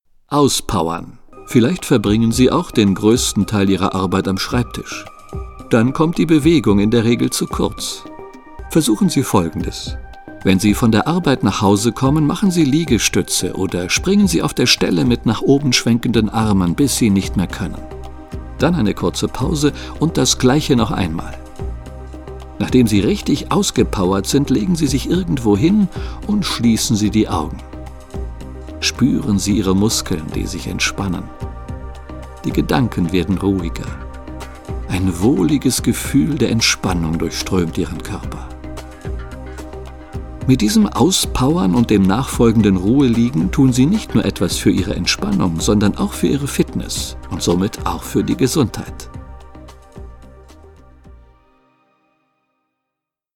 Die ruhige und einfühlsame Stimme des Sprechers
führt sie durch die einzelnen Übungen, die mit spezieller Entspannungsmusik unterlegt sind.